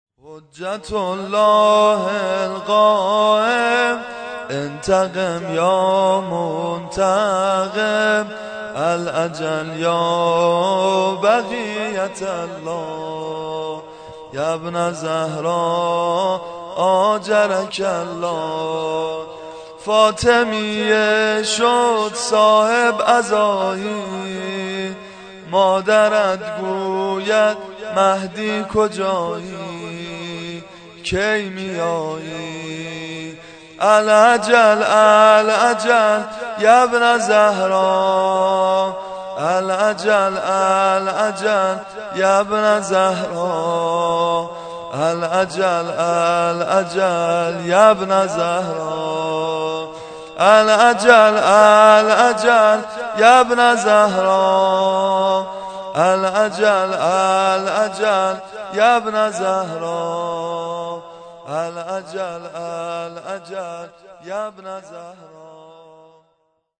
نوحه شهادت حضرت زهرا (س) ( حجتُ اللهِ القائم، اِنتَقِم یا مُنتَقِم)
شام غریبان